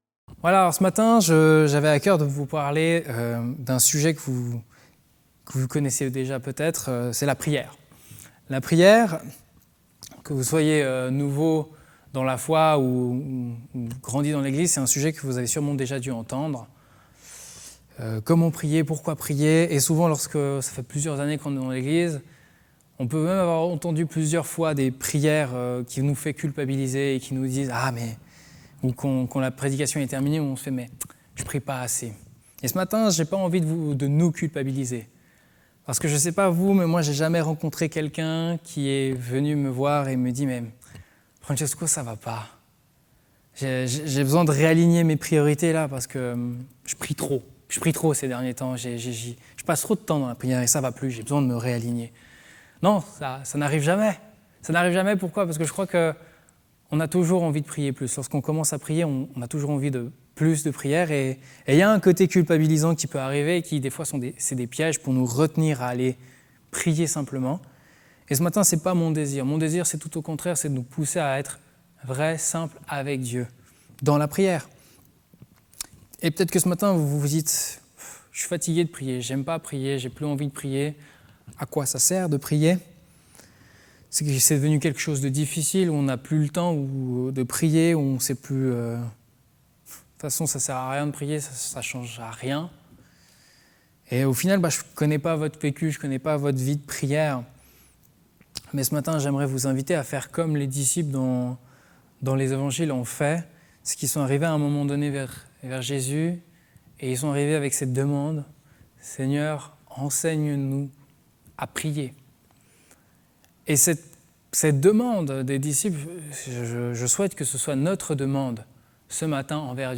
Culte du 2 août 2020 « la prière »